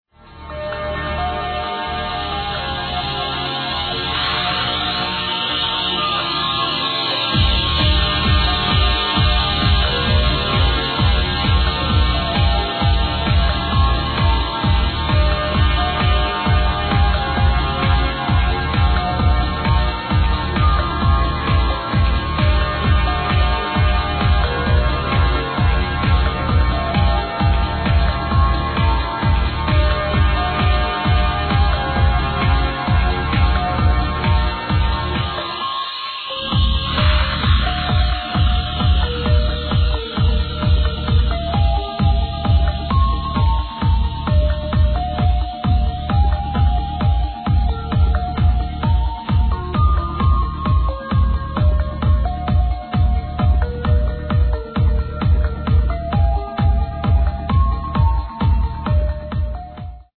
Nice proggy tune